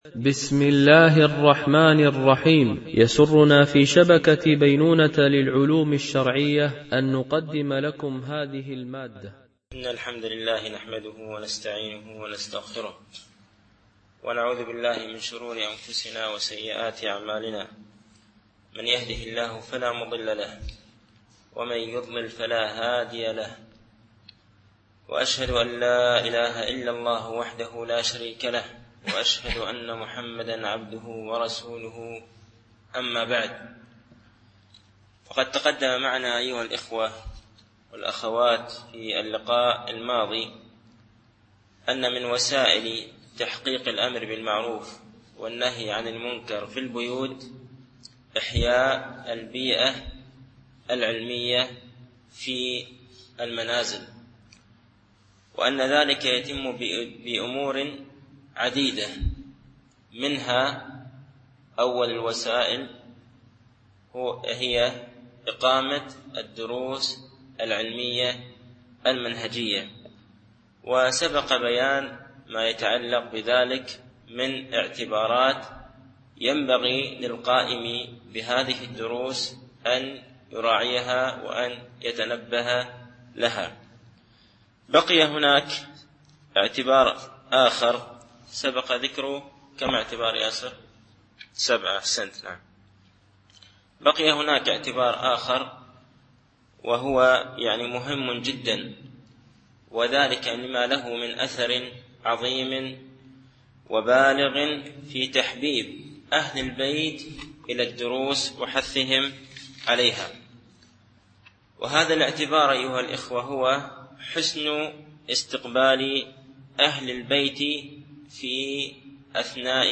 الألبوم: شبكة بينونة للعلوم الشرعية التتبع: 169 المدة: 73:33 دقائق (16.87 م.بايت) التنسيق: MP3 Mono 22kHz 32Kbps (CBR)